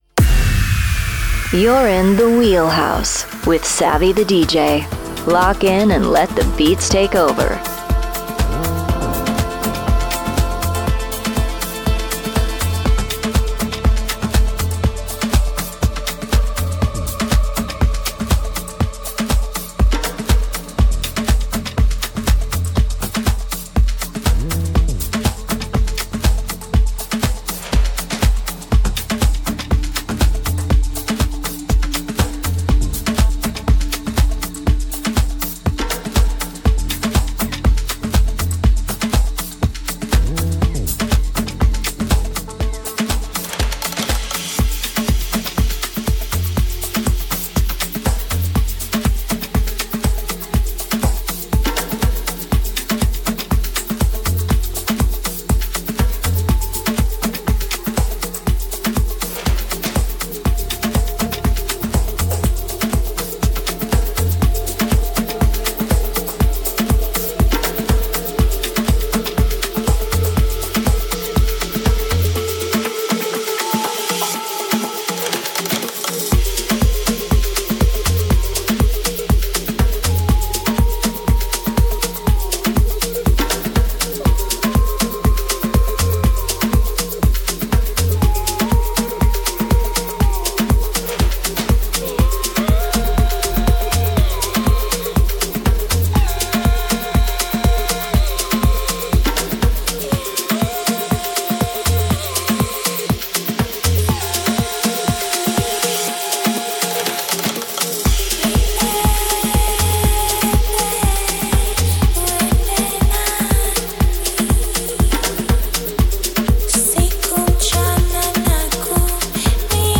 house music mix
Known for deep beats and energetic dancefloor vibes